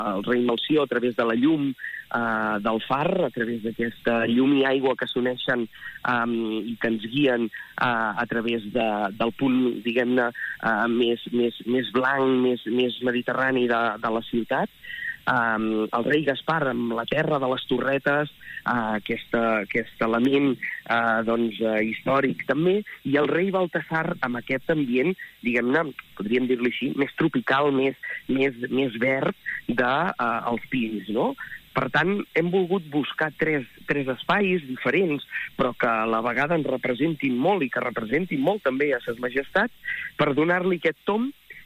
El tinent d’Alcaldia de Cultura, Josep Grima, ha parlat al matinal de Ràdio Calella TV, a la FM I +, de l’estrena de noves carrosses que representaran tant la identitat de la ciutat com de Ses Majestats.